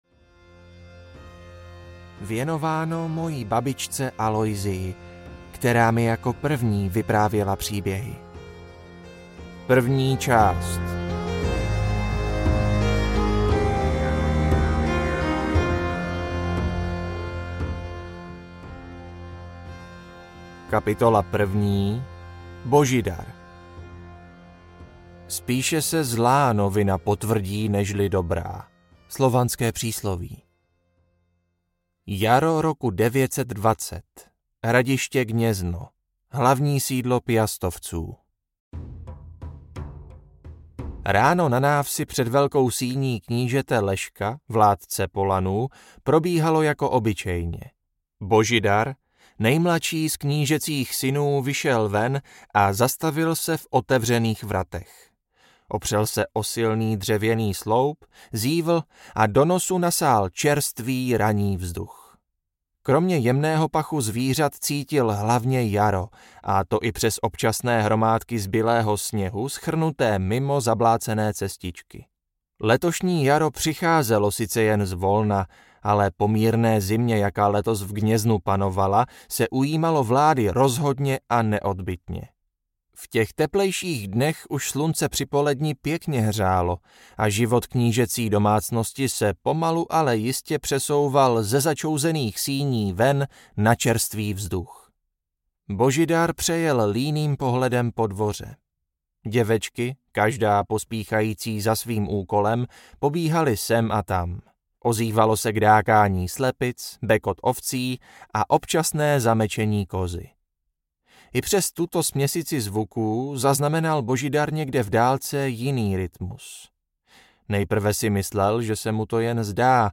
Ewraker I audiokniha
Ukázka z knihy